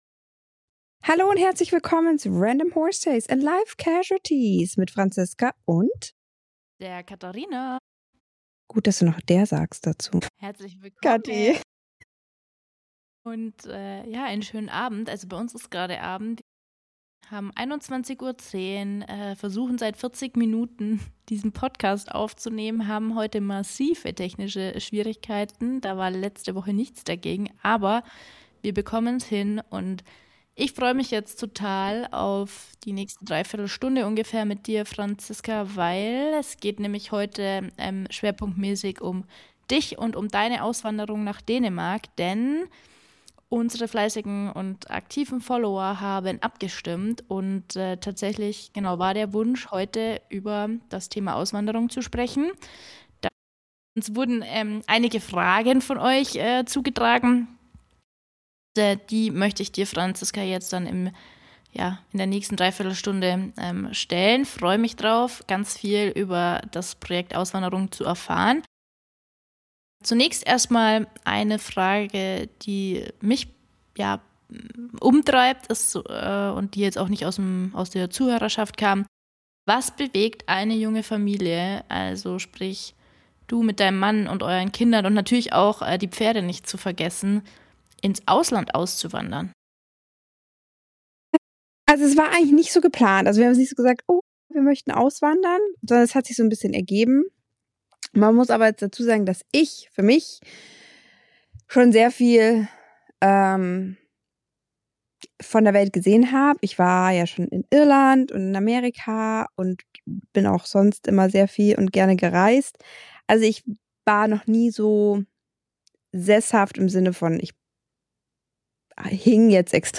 Sie ist ein offenes Gespräch über Mut, Zweifel, Unterstützung, Veränderung und die Erlaubnis, Wege zu gehen und sie auch wieder zu verändern.